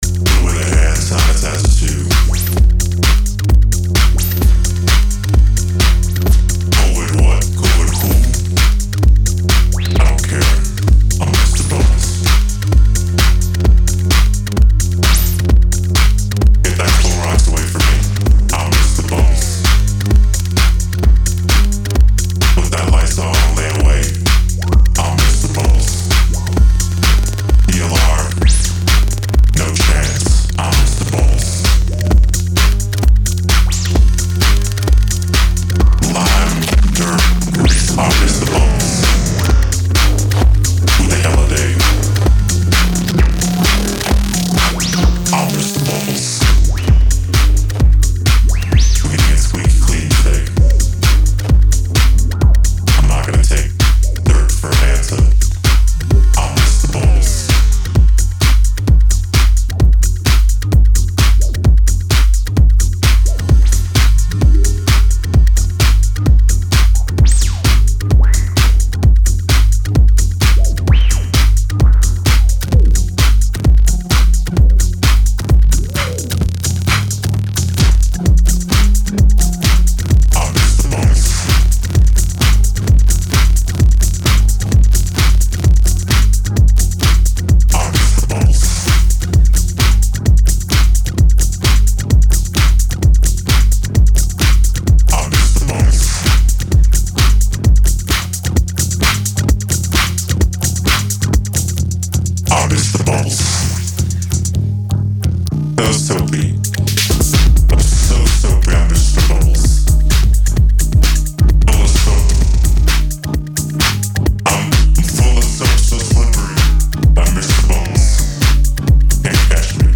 シカゴ・ゲットー・ハウスにフレンチ・エレクトロを合体、IDM/グリッチ的エディット感覚も注入された強刺激グルーヴ。